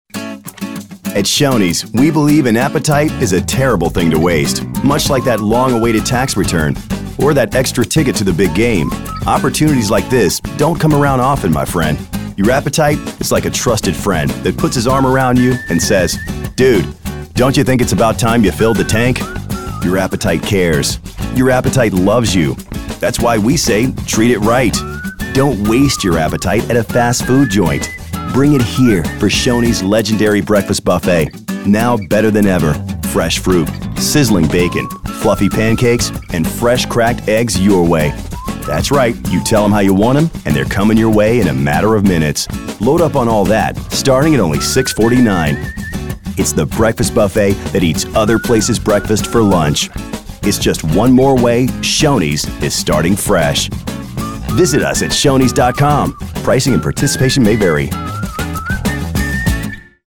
Cool Guy Next Door